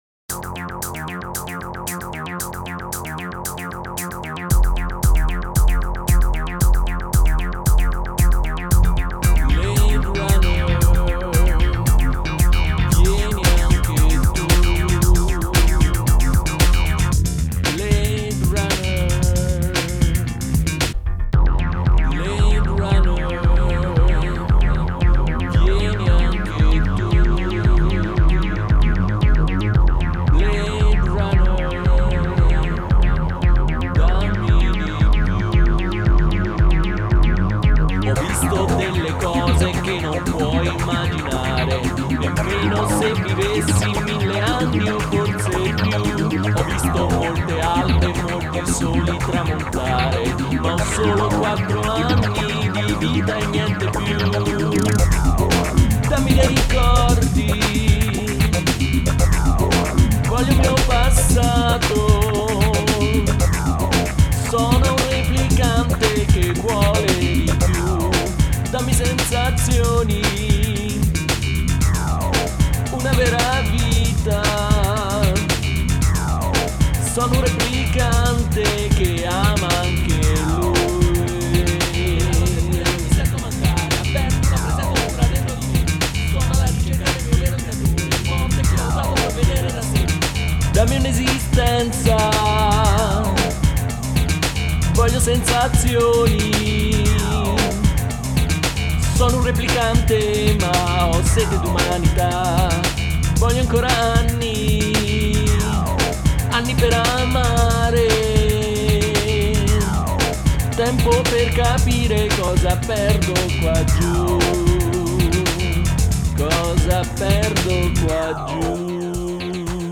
voce, cori, tastiere, chitarre e programmazione.